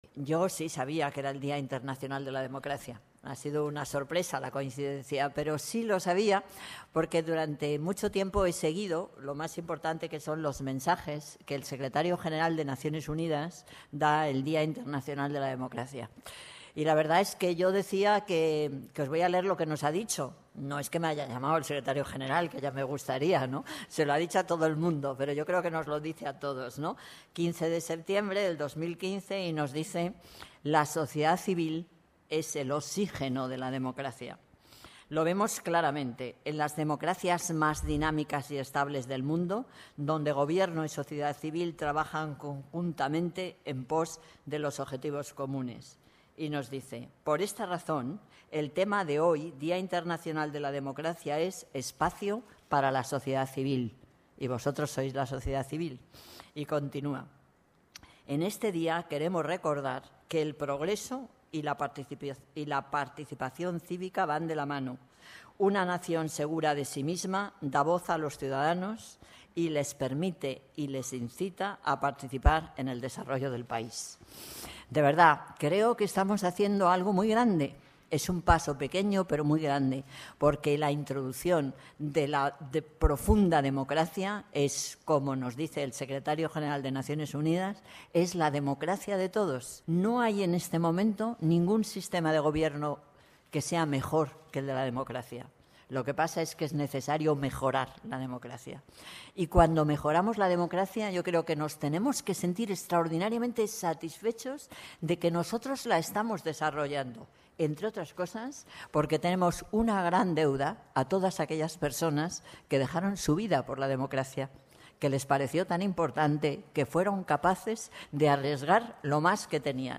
Nueva ventana:Manuela Carmena, alcaldesa de Madrid. Día Internacional de la Democracia.